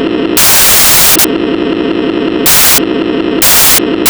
MANUAL DIESEL SOUNDS WITHOUT HORN, IDEAL FOR BASEBOARD
SPEED SET HALF WAY WITH VOLUME AT
THREE QUARTERS